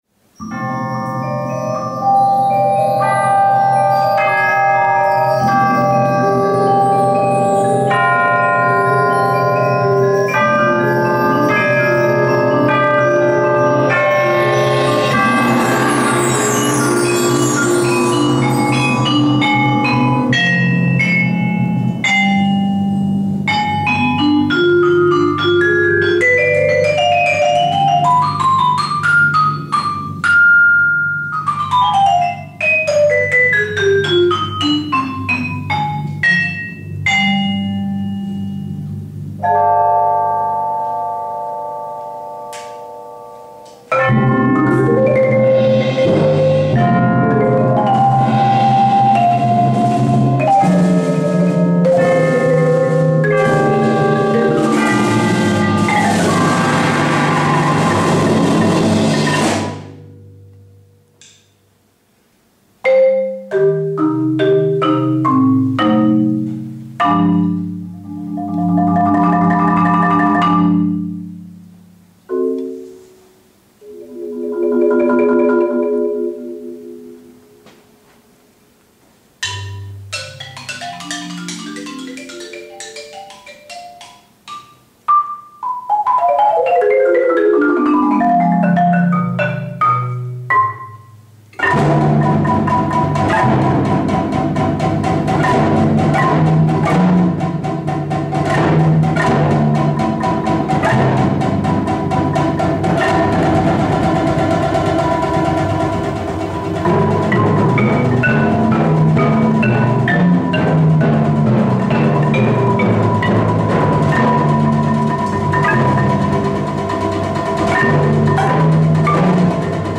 Genre: Soloist(s) with Percussion Ensemble
# of Players: 6 + solo
Solo Marimba (5-octave)
Vibraphone
Timpani
Percussion 1 (Chimes, Crotales, Bells, Tambourim)
Percussion 2 (Mark Tree, Bongo, Snare Drum, Tambourine)
Percussion 4 (Gong, Bass Drum, Temple Blocks, Surdo)